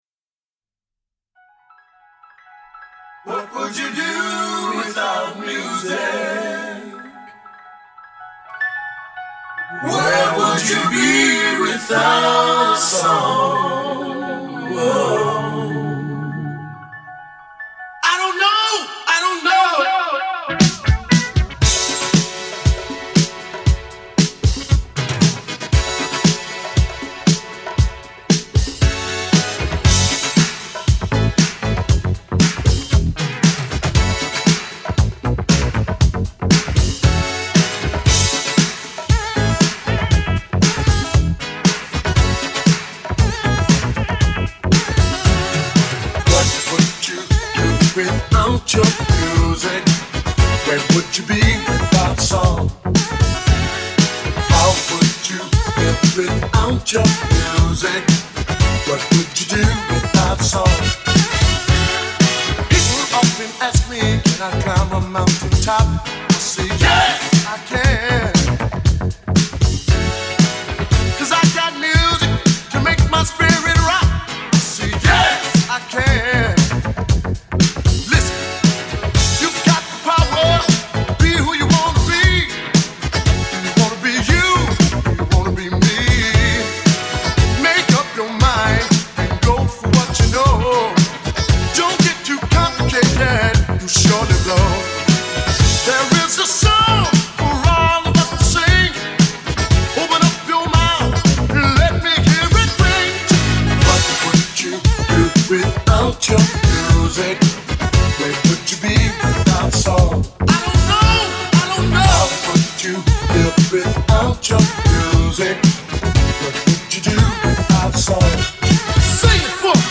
Sets from last night
Here are my two mini sets.